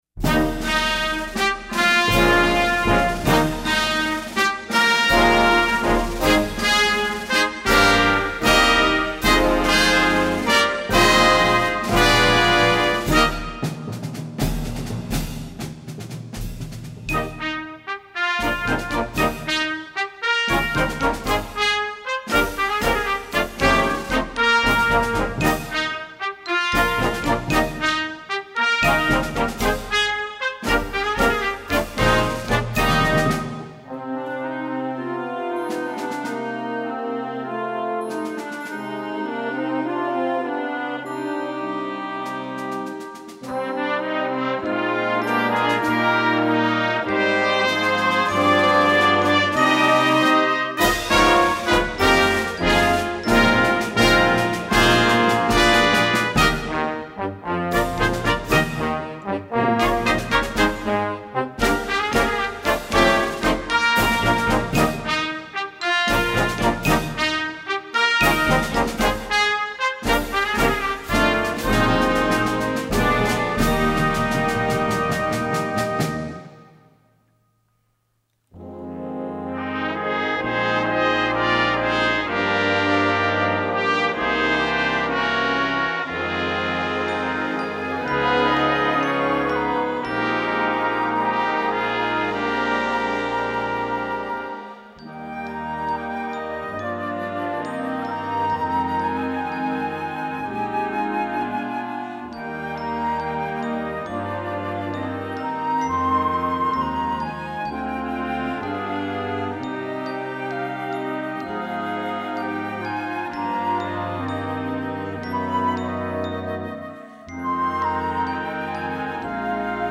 2 Besetzung: Blasorchester PDF